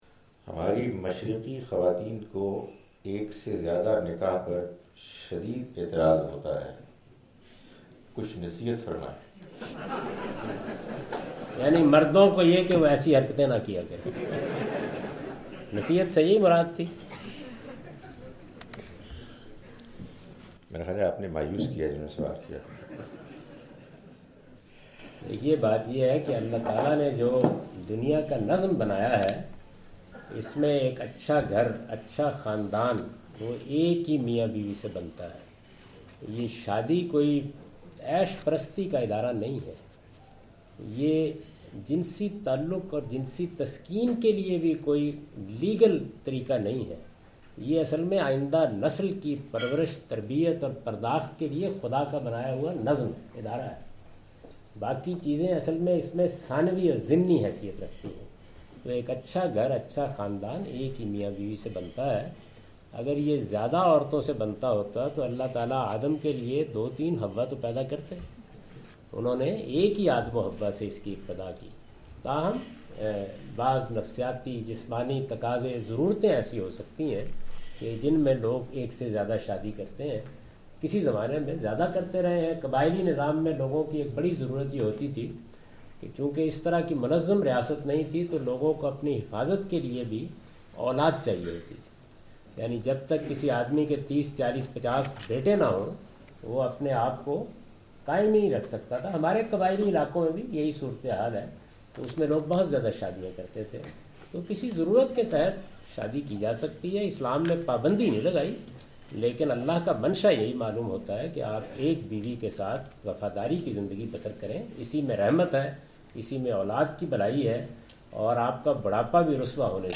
Javed Ahmad Ghamidi answer the question about "why women oppose polygamy" during his visit to Manchester UK in March 06, 2016.
جاوید احمد صاحب غامدی اپنے دورہ برطانیہ 2016 کے دوران مانچسٹر میں "خواتین تعدد اذواج کی مخالف کیوں ہیں؟" سے متعلق ایک سوال کا جواب دے رہے ہیں۔